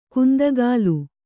♪ kundagālu